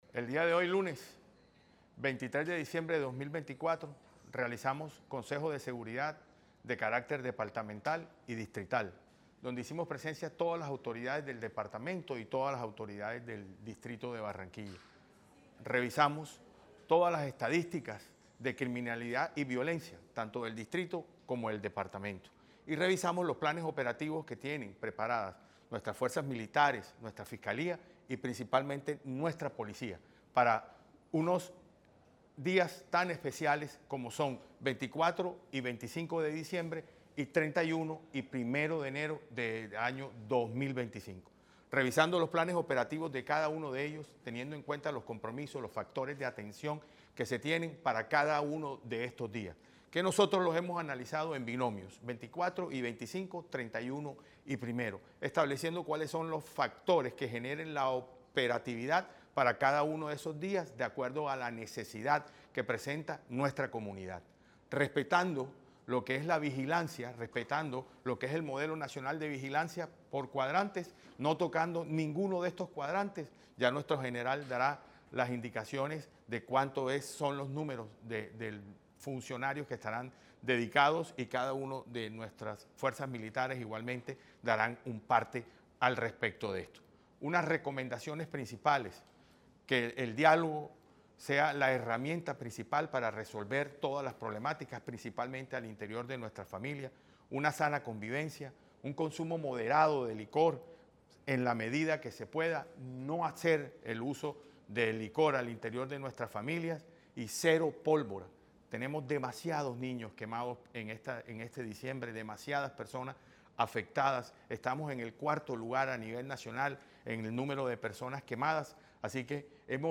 AUDIO -YESID TURBAY – CONSEJO DE SEGURIDAD:
BB-100-AUDIO-YESID-TURBAY-CONSEJO-DE-SEGURIDAD.mp3